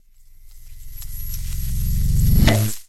Звуки магнита